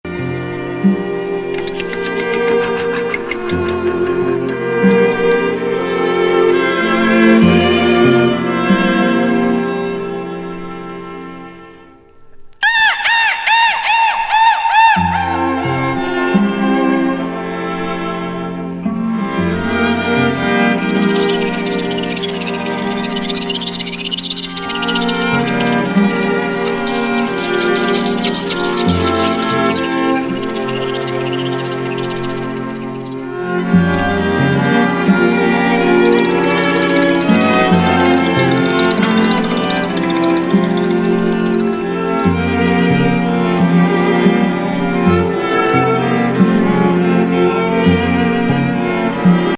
エレクトロニクスの静寂前衛B面